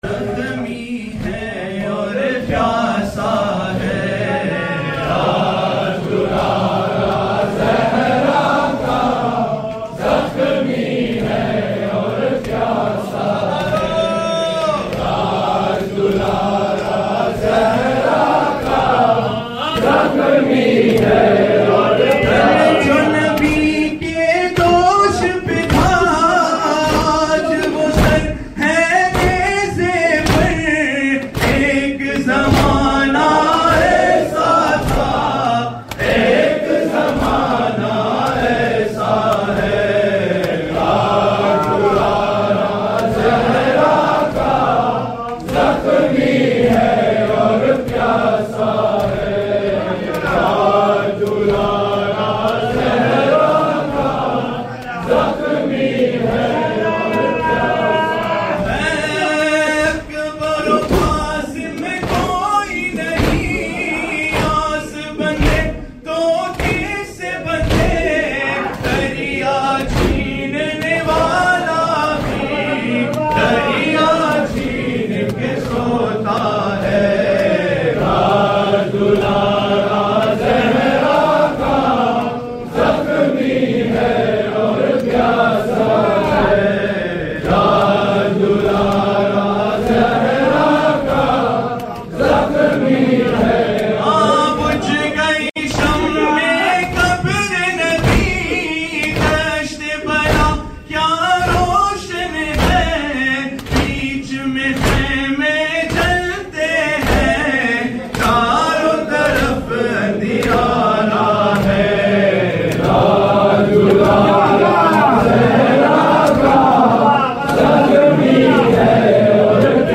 Submit lyrics, translations, corrections, or audio for this Nawha.